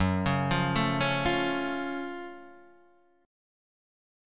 F# Minor Major 7th